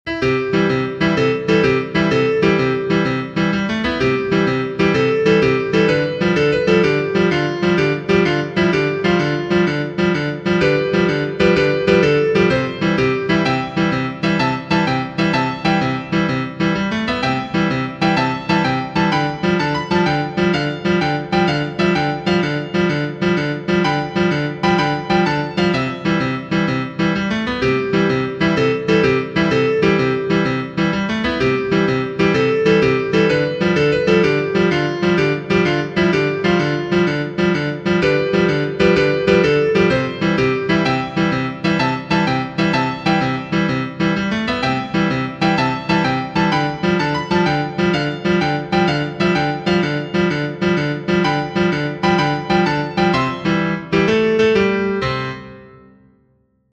Genere: Sociali e Patriottiche
è una canzone popolare emiliana